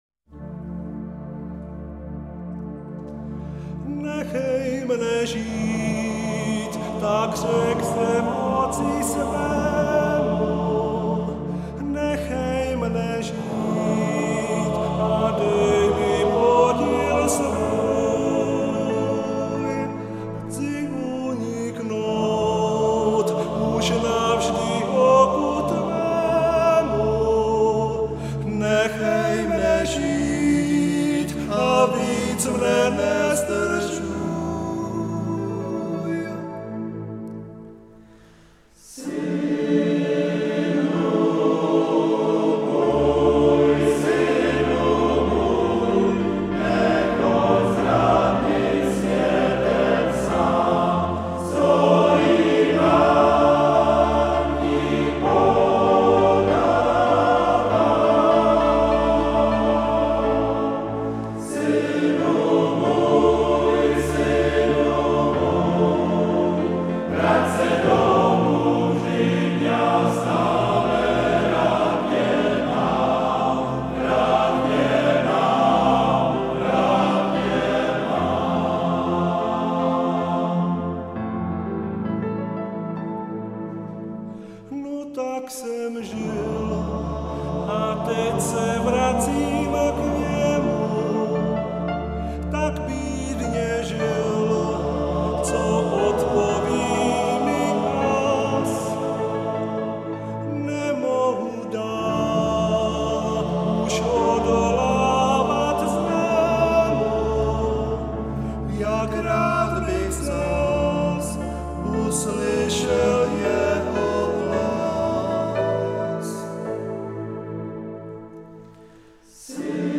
Synu můj – 1 verze (mužský pěvecký sbor)